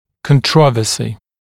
[kən’trɔvəsɪ][кэн’тровэси]спор, дискуссия, полемика